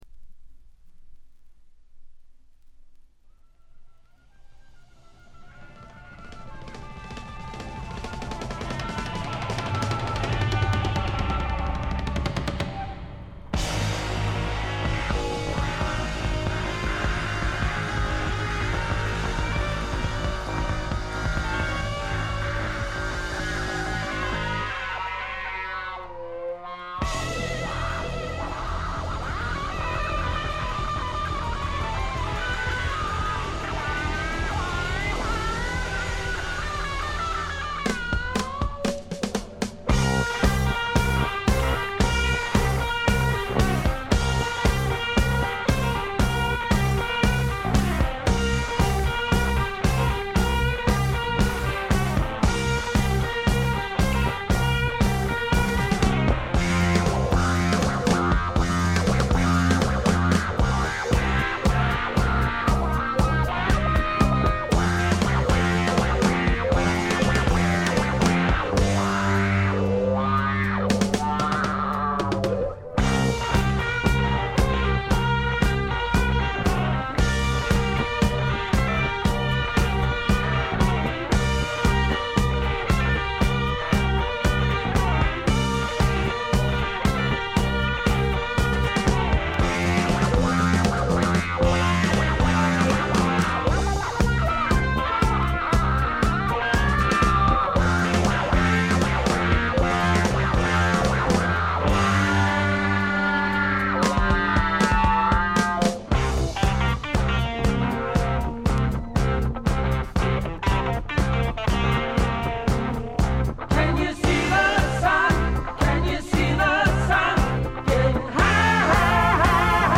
Drums, Percussion